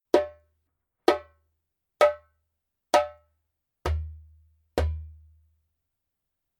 Wood : レッドウッド Red Wood (Diala, Acajou, Bois Rouge)
鳴りの感じはマリ・ギニア中間的な感じか。芯があって輪郭がくっきりとした音がします。